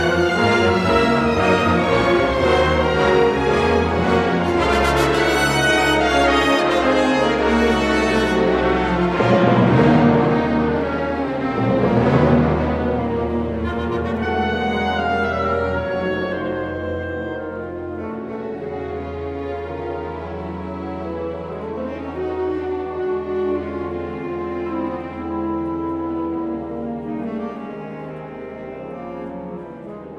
"templateExpression" => "Musique orchestrale"